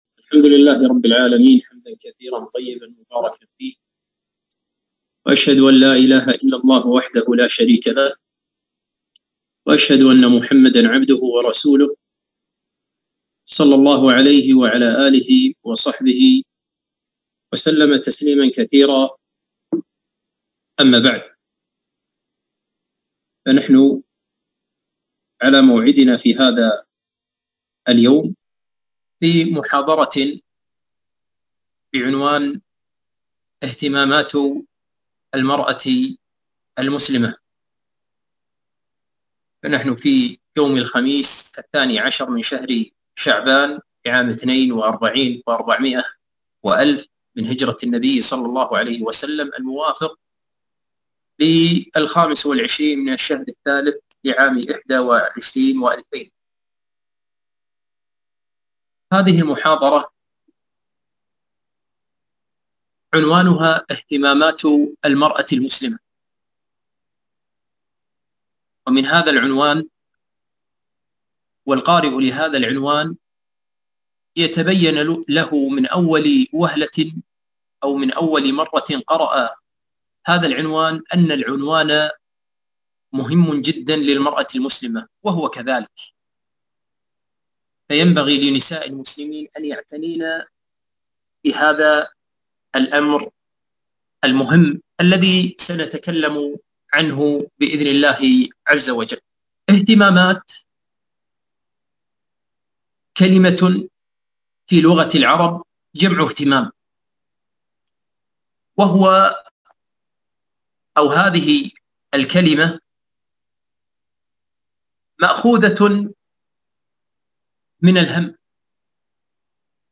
محاضرة - اهتمامات المرأة المسلمة